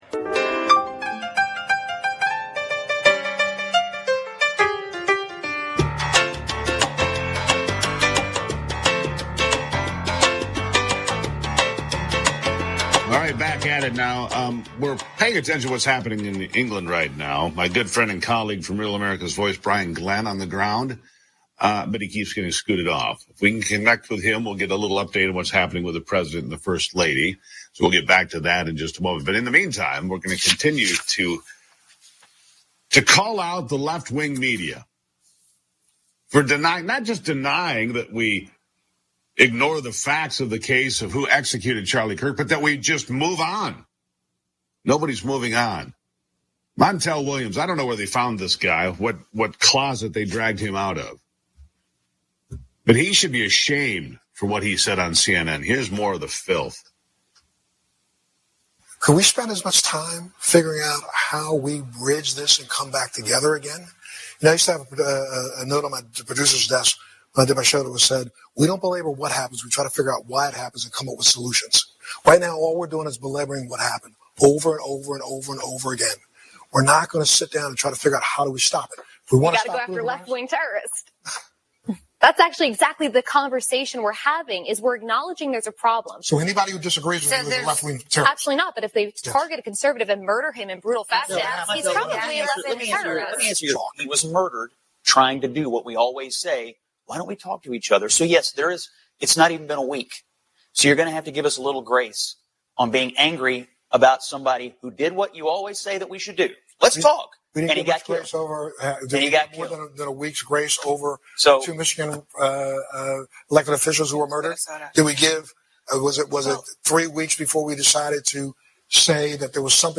Talk Show
MICHIGAN’S #1 SYNDICATED MORNING RADIO SHOW